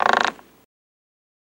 Rope Creaks Moan